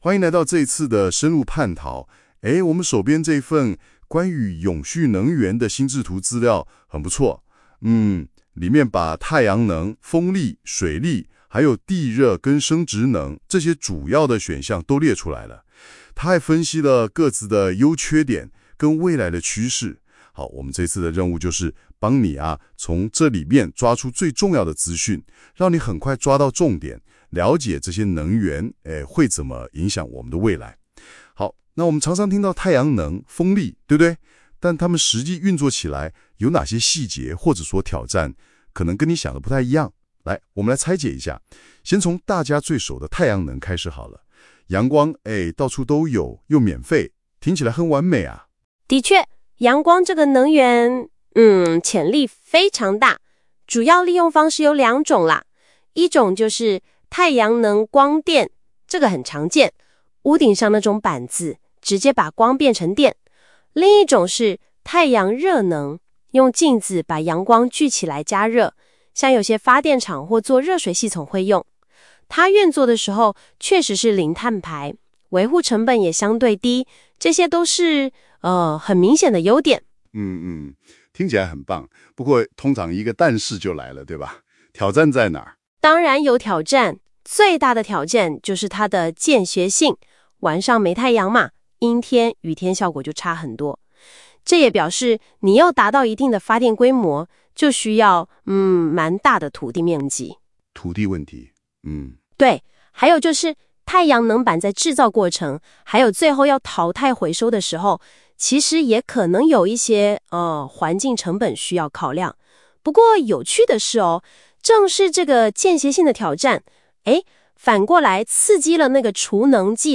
• 語音摘要
Gemini Canvas 的語音摘要功能，能將複雜的文字內容轉化為生動的語音。最特別的是，產生的語音摘要能有男女對話，聽起來不會死板，聲音自然有機械音，也是很重要的特點。